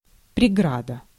Ääntäminen
IPA : /ˈbæɹɪə(ɹ)/